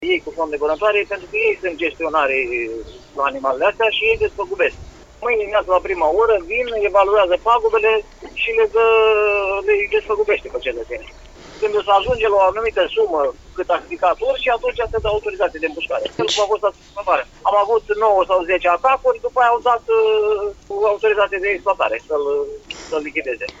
În cursul zilei de mâine, reprezentanții Asociaţiei Judeţene a Vânătorilor şi Pescarilor Sportivi Buzău vor veni în comuna Colți pentru a evalua pagubele produse de către urși, a menționat primarul Pavel Rădulescu:
Primar-Colti-3.mp3